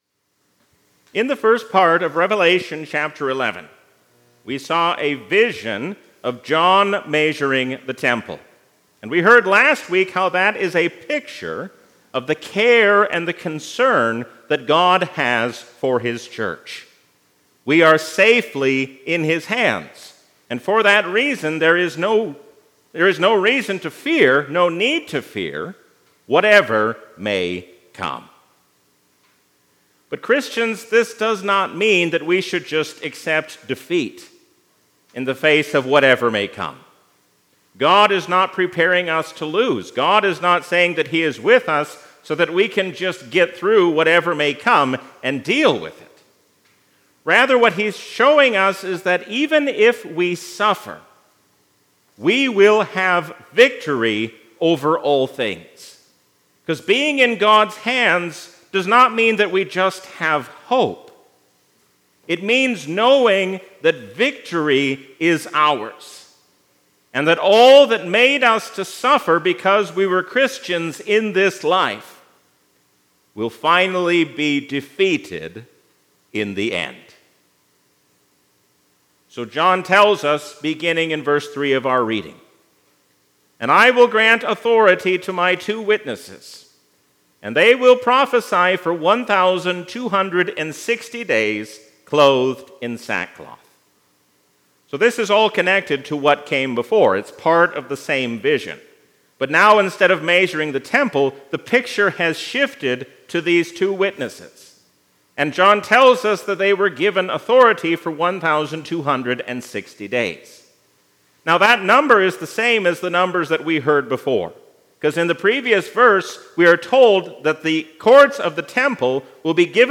A sermon from the season "Trinity 2021." Stand firm against worldly powers, because Jesus reigns as King forever.